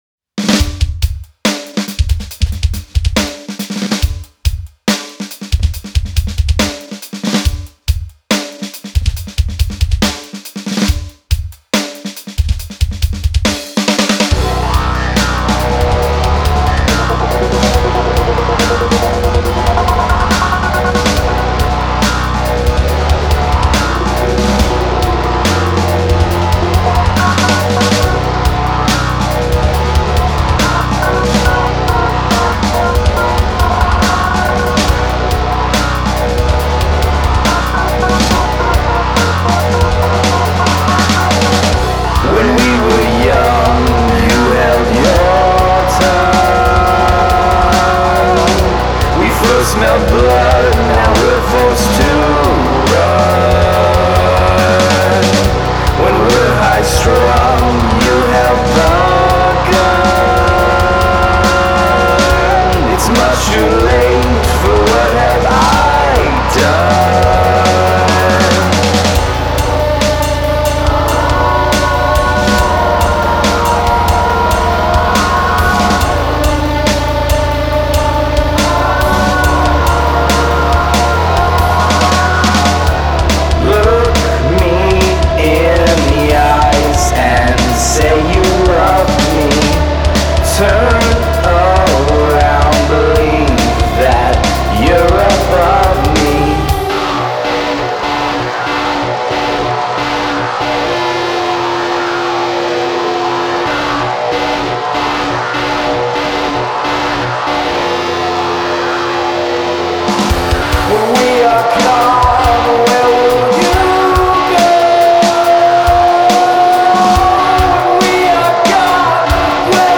drums, bass, keys, vocals. tracking